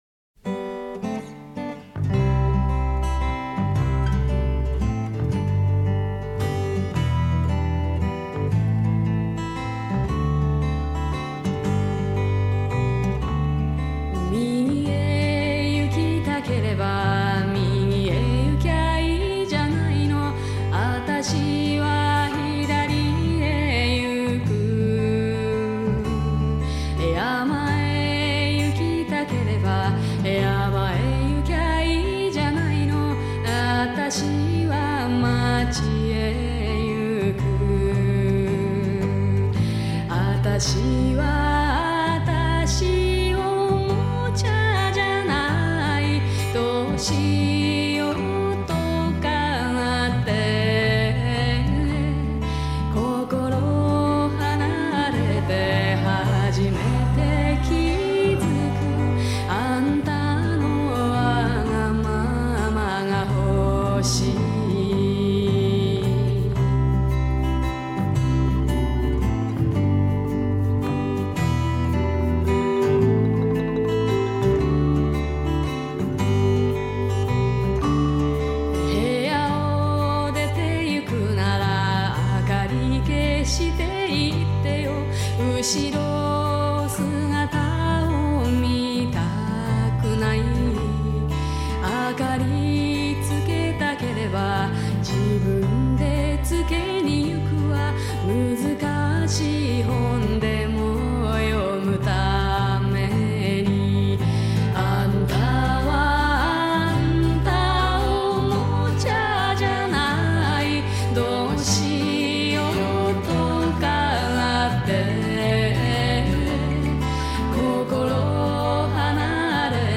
民谣风的经典专辑